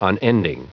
Prononciation du mot unending en anglais (fichier audio)
Prononciation du mot : unending